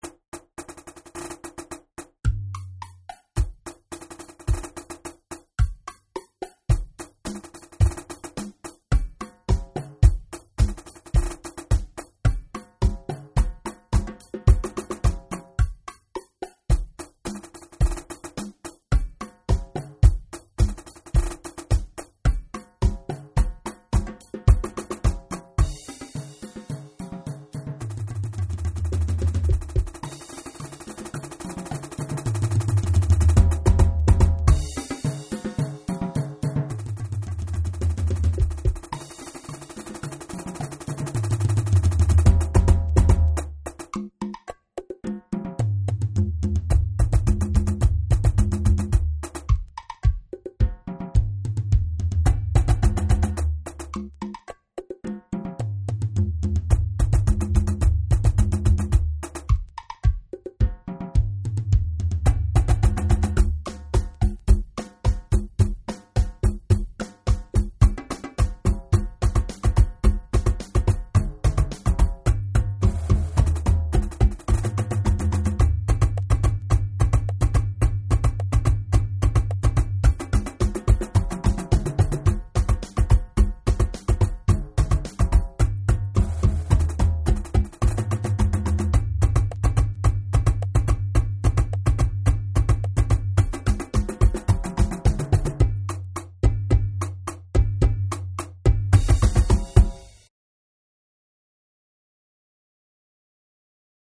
Jeugd Ensemble